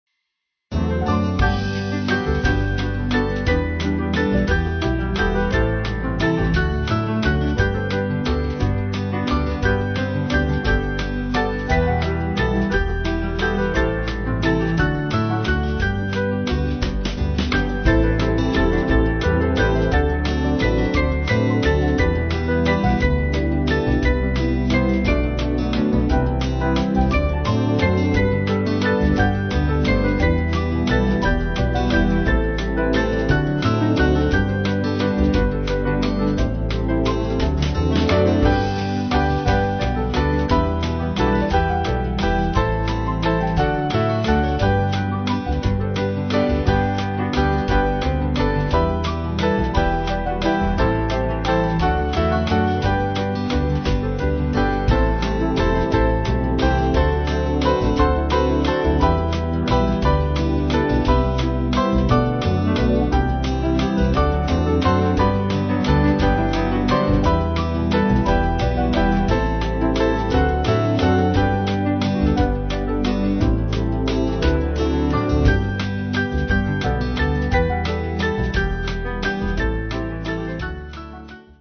Small Band
(CM)   4/G-Ab
Brighter version